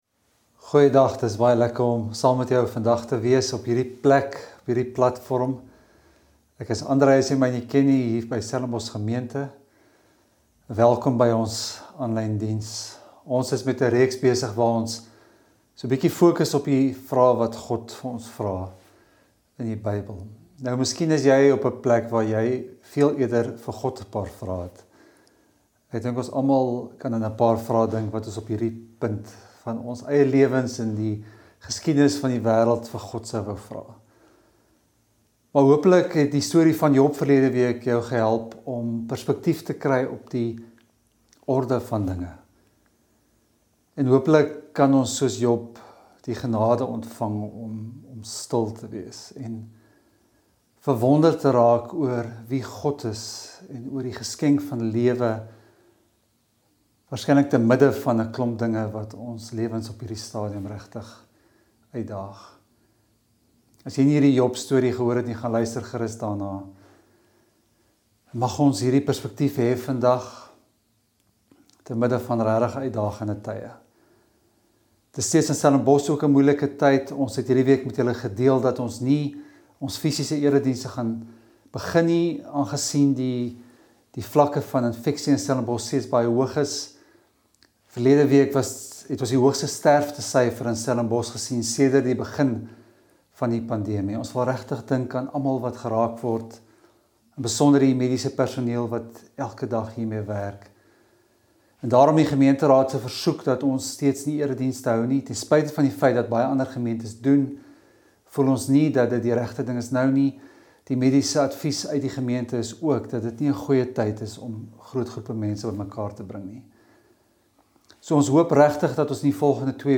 Stellenbosch Gemeente Preke 15 Augustus 2021 || Godsvrae - Wat is in jou hand?